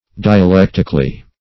\Di`a*lec"tic*al*ly\